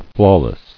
[flaw·less]